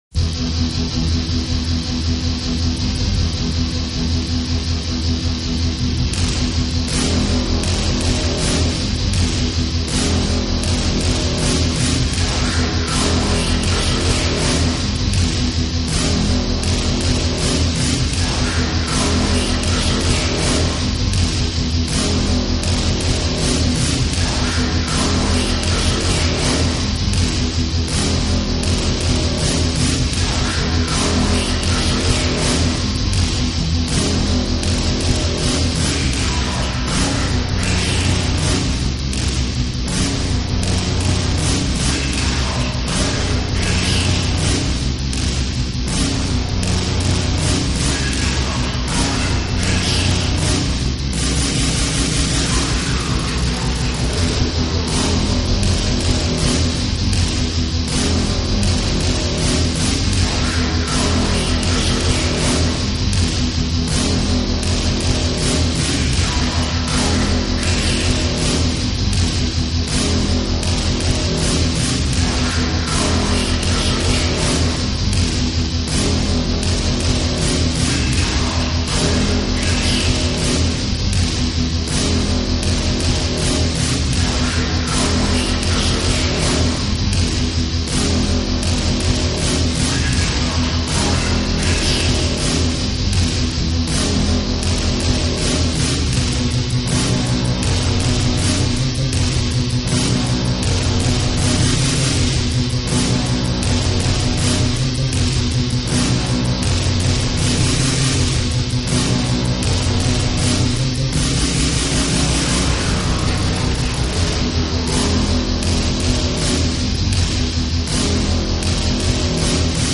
Instruments Guitar, bass, percussion